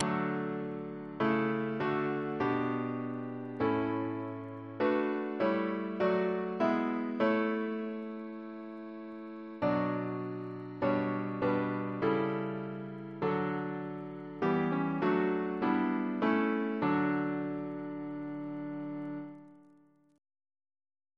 Double chant in D Composer: Thomas Attwood (1765-1838), Organist of St. Paul's Cathedral Reference psalters: ACB: 264; H1982: S230 S234; OCB: 222; PP/SNCB: 124; RSCM: 7